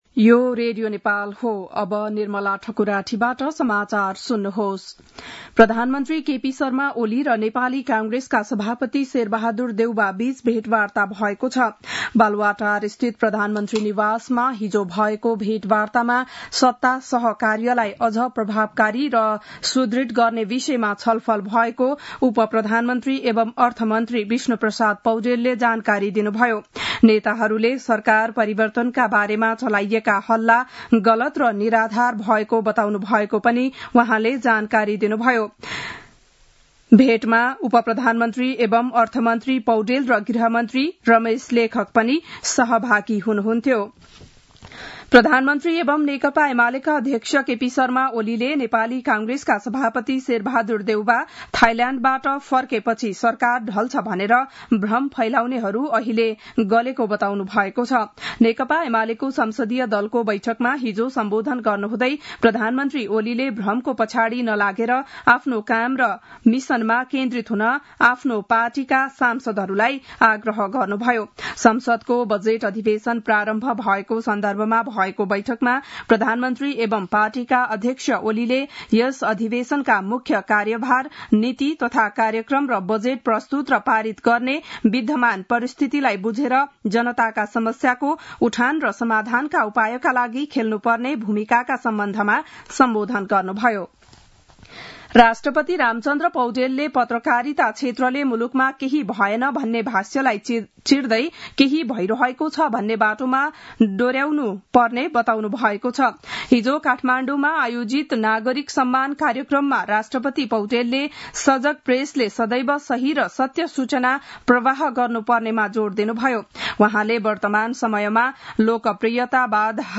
बिहान ११ बजेको नेपाली समाचार : १४ वैशाख , २०८२
11-am-news-1-13.mp3